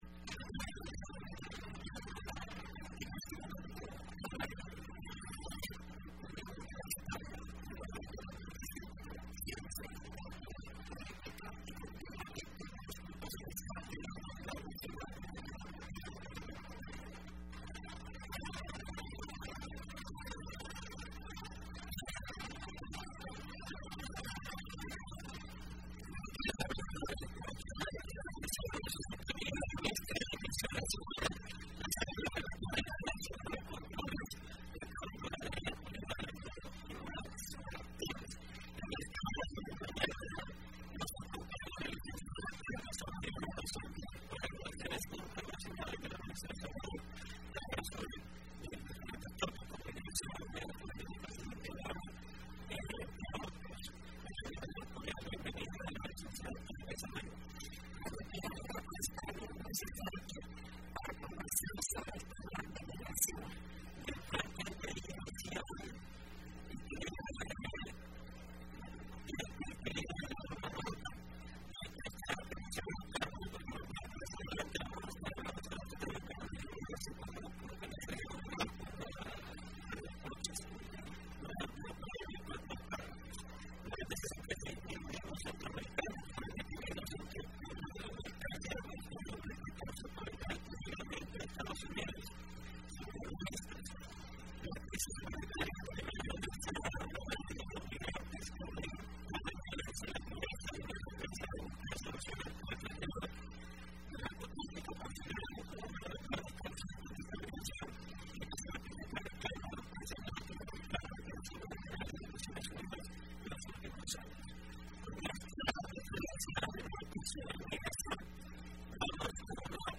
Entrevista Opinión Universitaria (9 febrero 2015): Migración y políticas migratorias estadounidenses impulsadas por el presidente Barack Obama